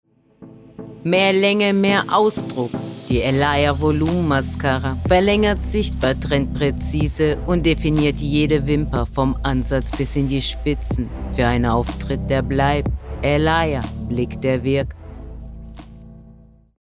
Deutsche Voice-over-Sprecherin – warm & klar.
Studioqualität.
Sprechprobe: Werbung (Muttersprache):
German voice-over artist (warm, clear female voice) for ads, explainer videos and narration.